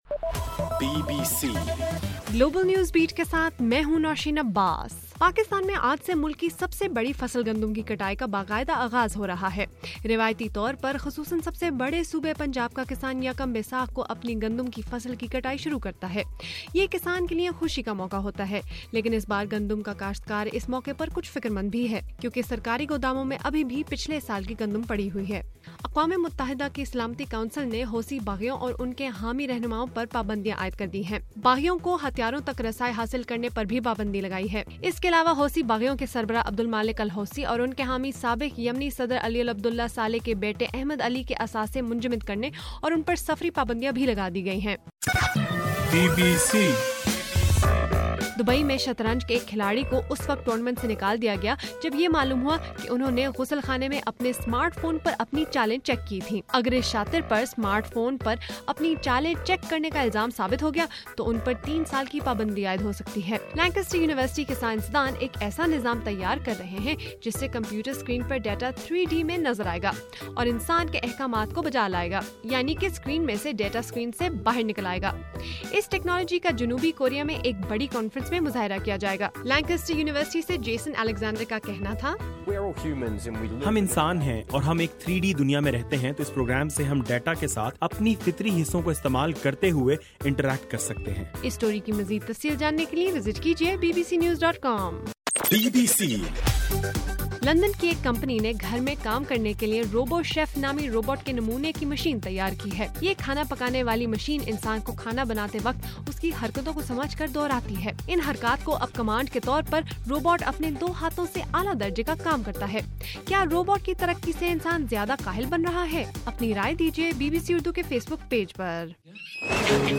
اپریل 14:رات 12 بجے کا گلوبل نیوز بیٹ بُلیٹن